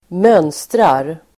Uttal: [²m'ön:strar]